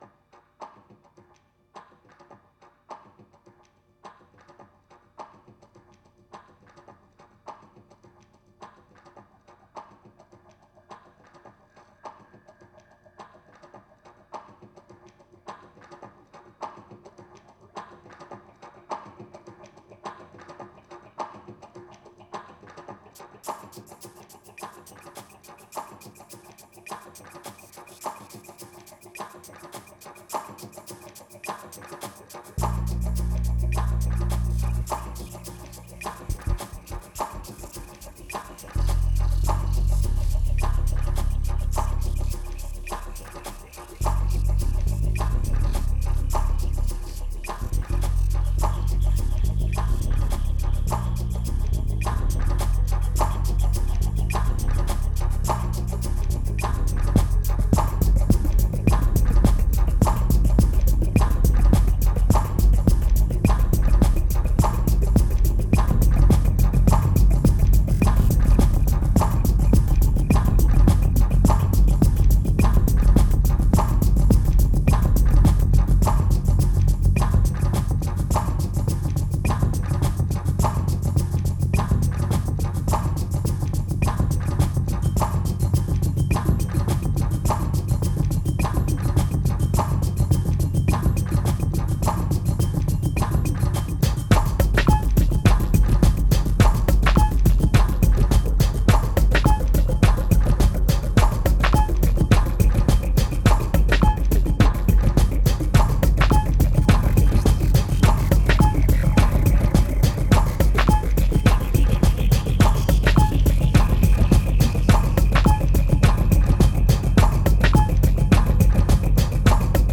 2180📈 - 59%🤔 - 105BPM🔊 - 2011-01-07📅 - 45🌟